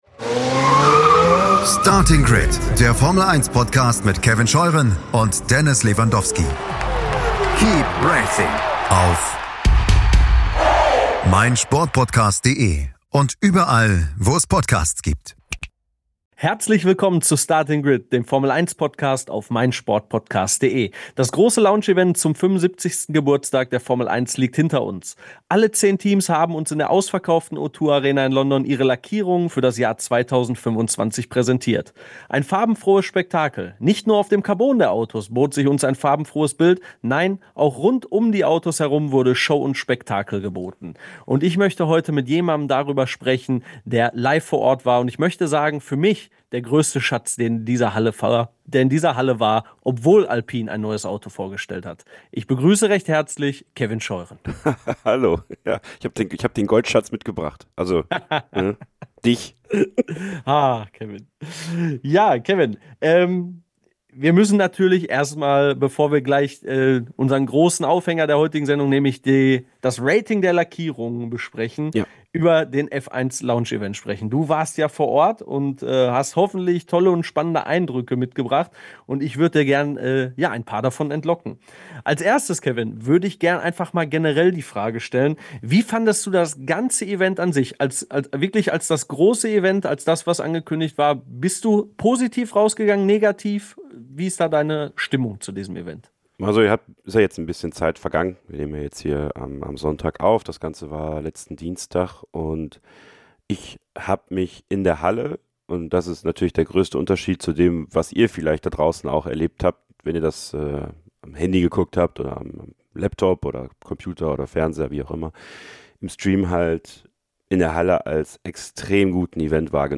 Dazu gibts Eindrücke von vor Ort, denn ...